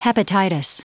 Prononciation du mot hepatitis en anglais (fichier audio)
Prononciation du mot : hepatitis